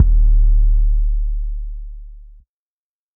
TC 808 8.wav